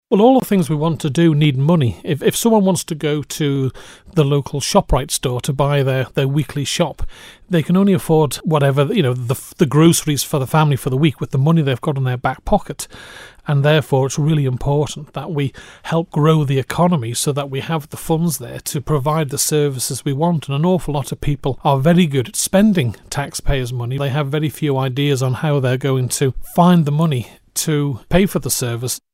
The 50-year-old listed his future priorities as reforming public sector pensions and protecting the Island against further VAT losses. During an interview with Manx Radio on Monday evening, Mr Quayle said most political issues came down to one major problem: Listen to this audio